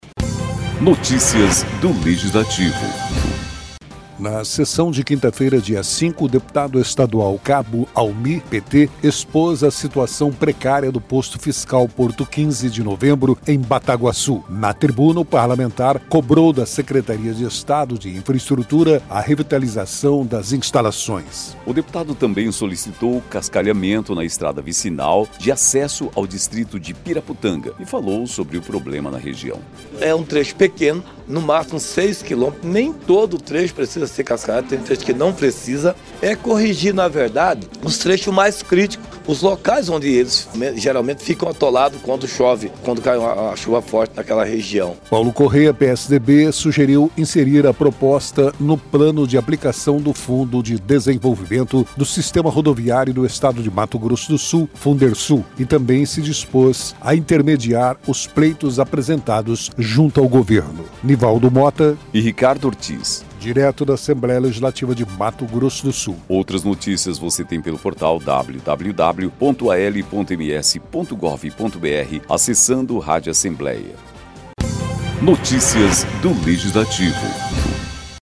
Na sessão desta quinta-feira (5), o deputado estadual Cabo Almi (PT) expôs a situação precária do Posto Fiscal Porto XV de Novembro, em Bataguassu.